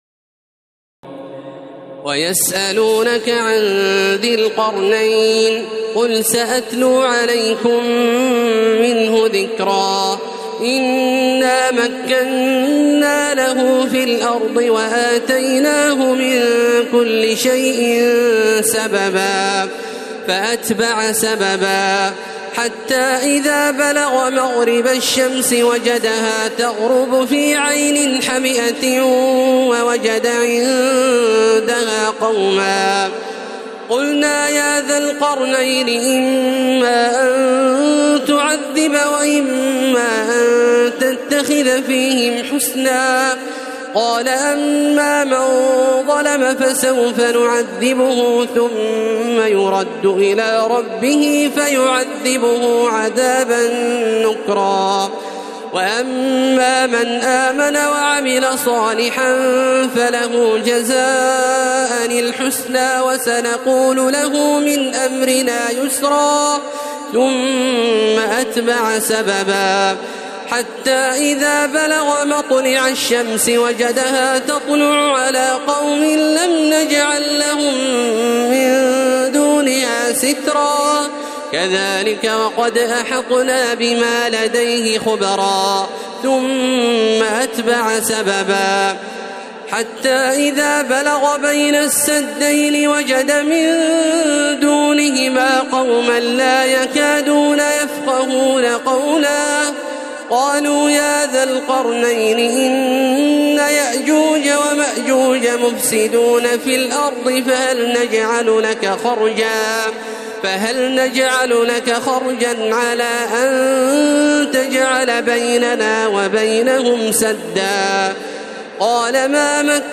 تراويح الليلة الخامسة عشر رمضان 1432هـ من سورتي الكهف (83-110) و مريم كاملة Taraweeh 15 st night Ramadan 1432H from Surah Al-Kahf and Maryam > تراويح الحرم المكي عام 1432 🕋 > التراويح - تلاوات الحرمين